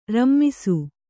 ♪ rammisu